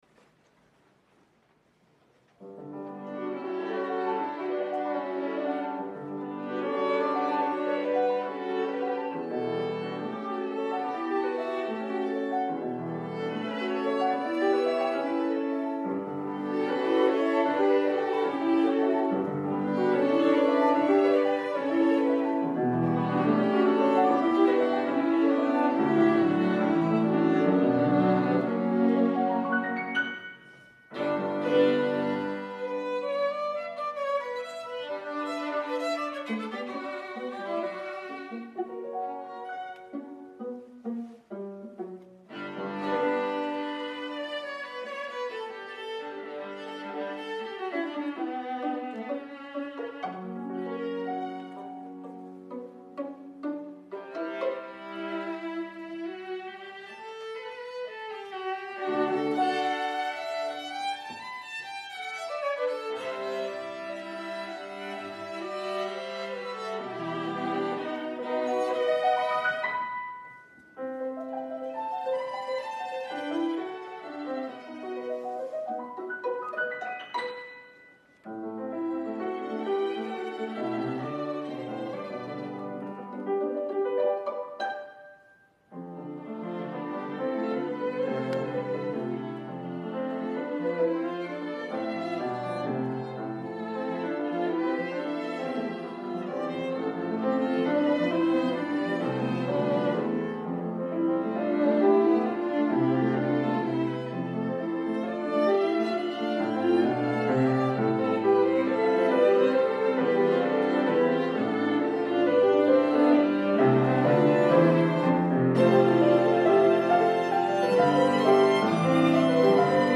Voicing: Piano Trio